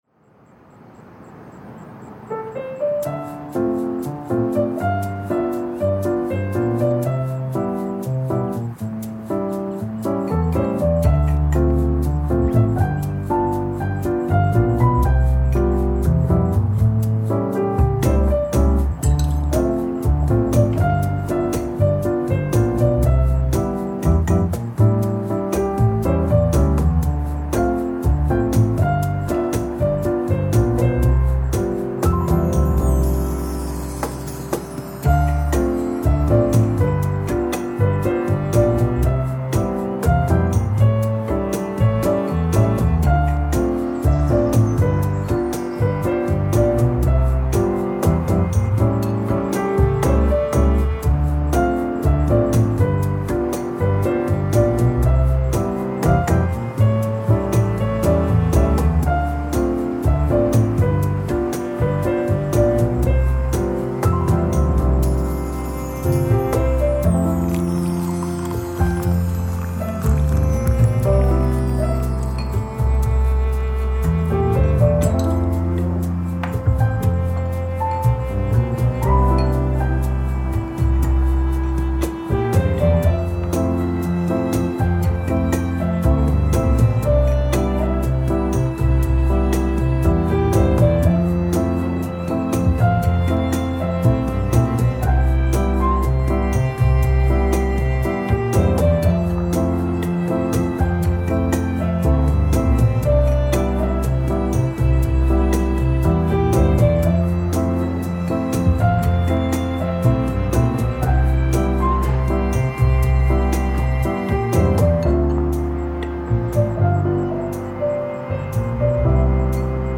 ジャズ ポップ
まったり落ち着く チル 日常
ピアノとウッドベース、ストリングスのまったり落ち着くけど、チルすぎないBGMです。
SEありver